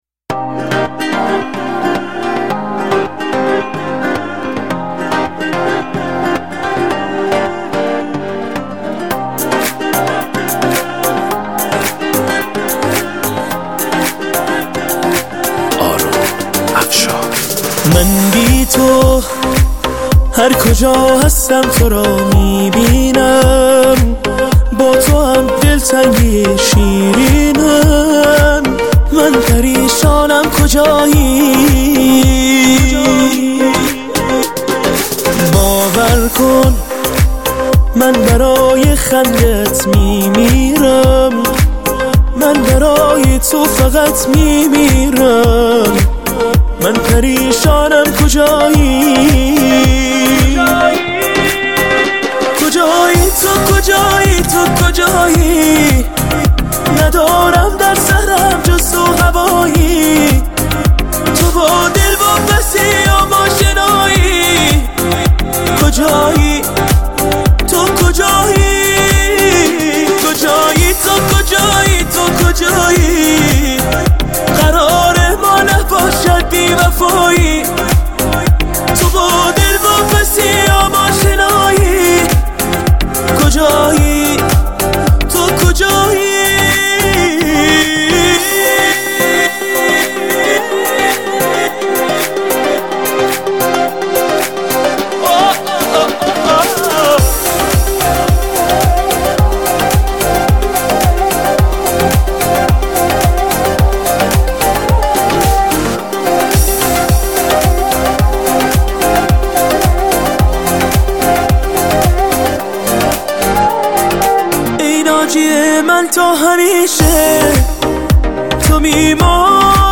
آهنگ جدید پاپ